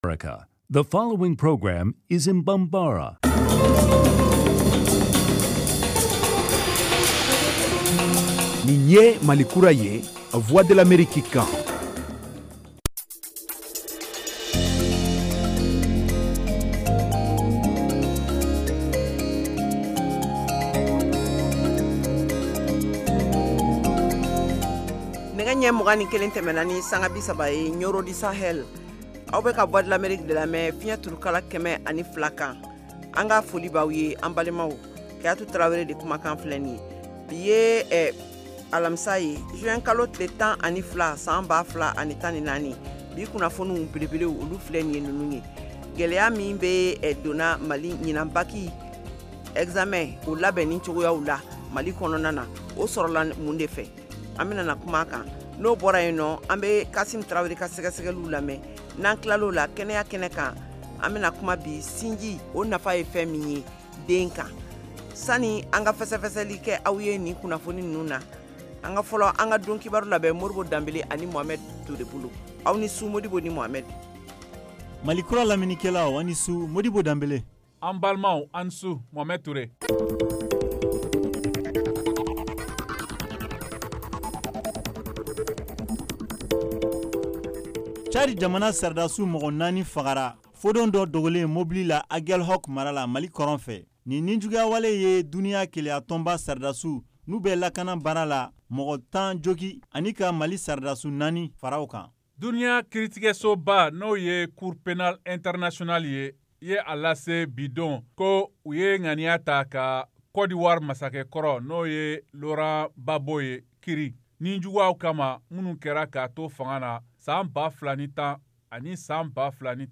en direct de Washington. Au menu : les nouvelles du Mali, les analyses, le sport et de l’humour.